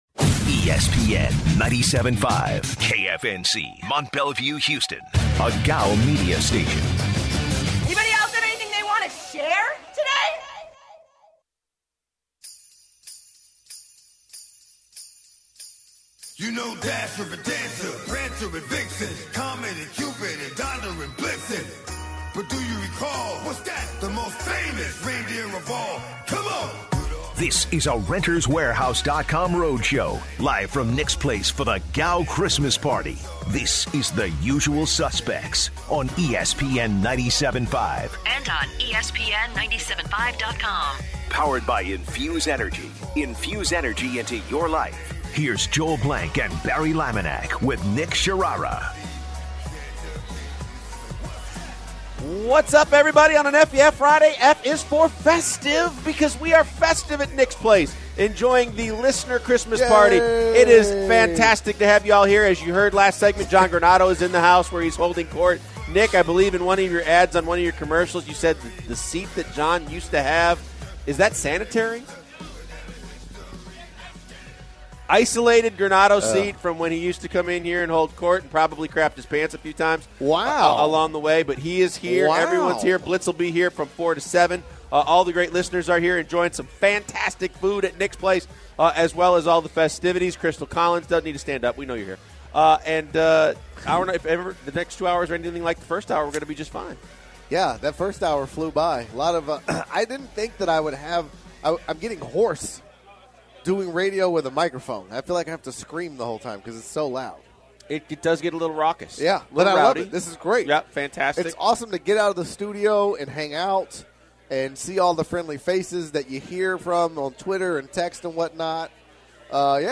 The guys are on location at Nick’s Place and start the second hour talking about the upcoming Texans/Eagles game. They look more into each team and what it will take for them to win. More shenanigans ensue at Nick’s Place, including a push-up contest.